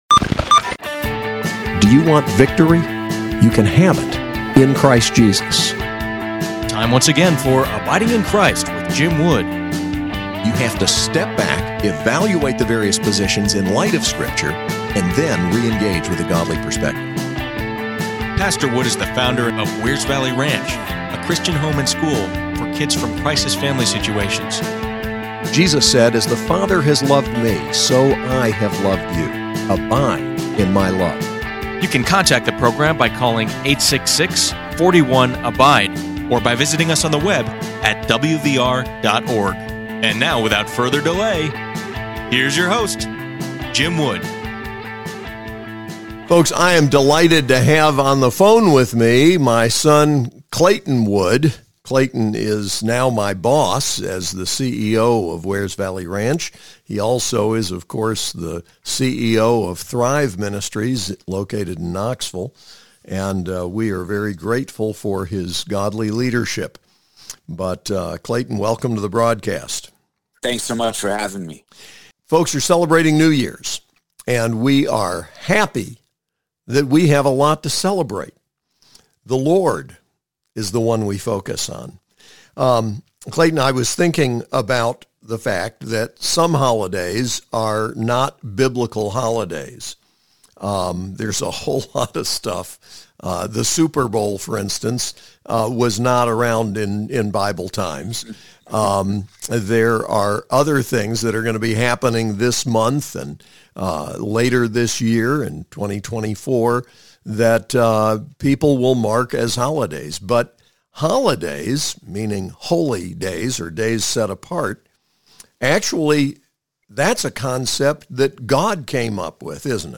Program for 1/1/24 Interview